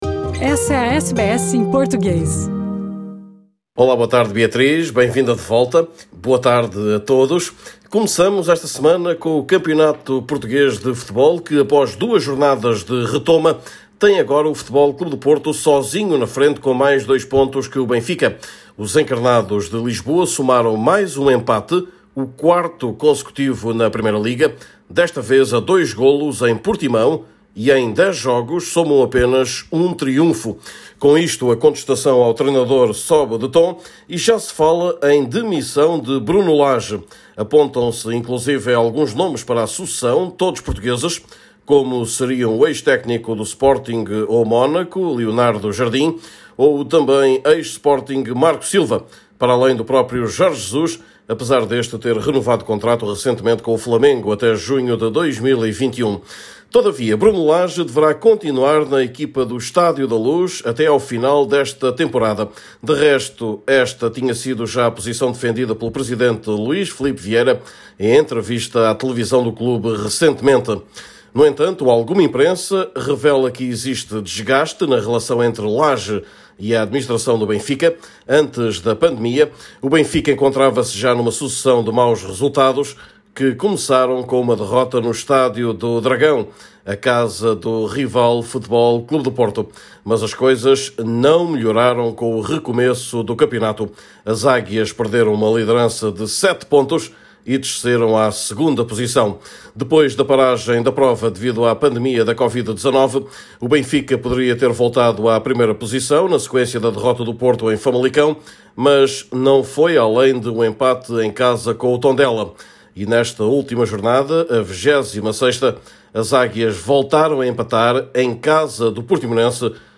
Neste boletim semanal, fala-se também da hipótese Lisboa para a fase final da Champions League desta época, após a desistência de Istambul, que tinha constituído a escolha inicial da UEFA.